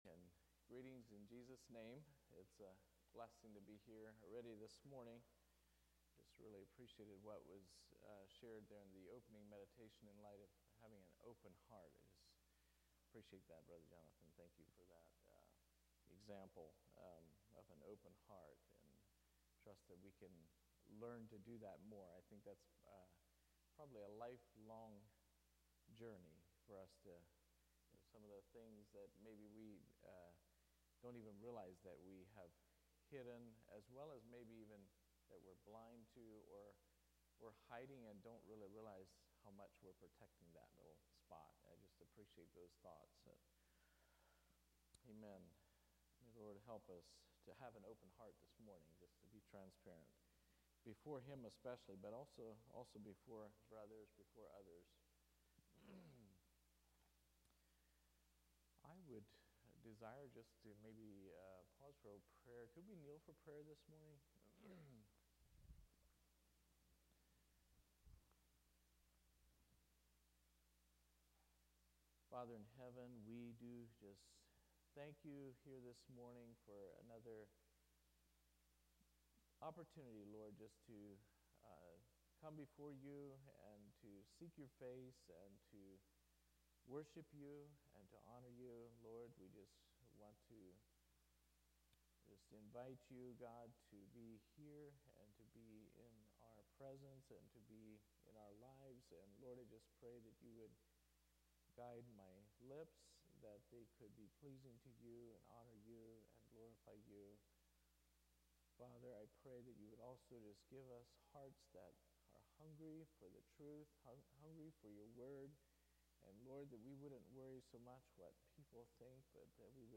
2024 Sermons - Dayton Christian Fellowship